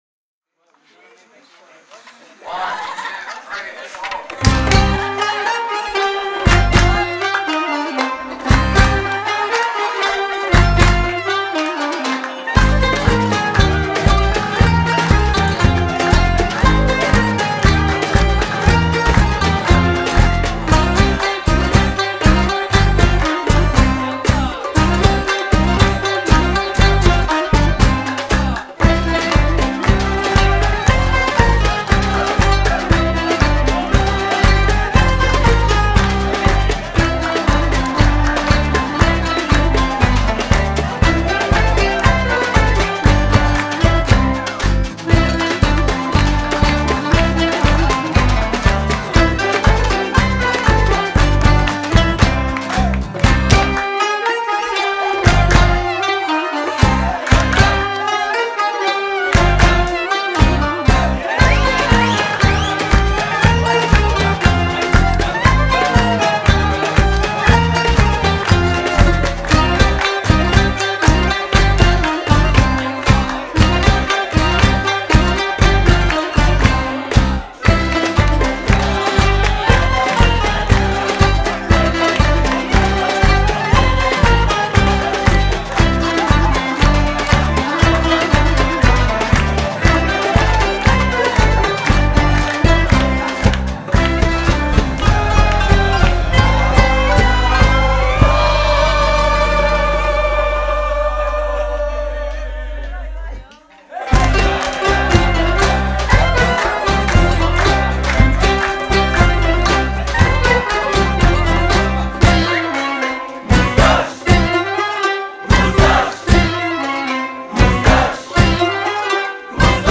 Electro – Ethnic – Reggae group
Genre: World